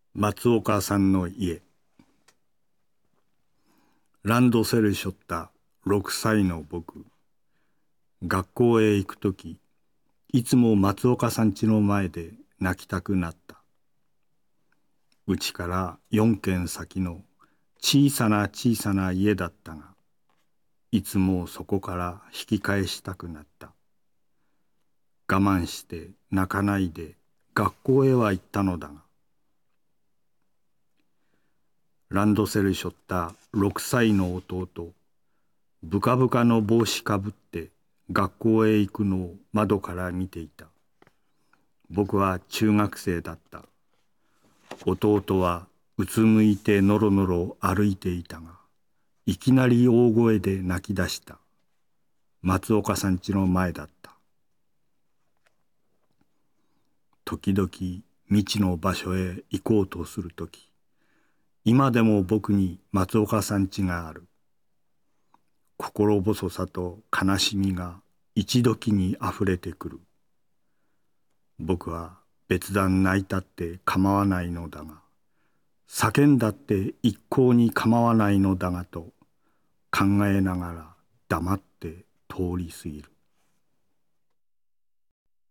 辻征夫の「まつおかさんの家」を読む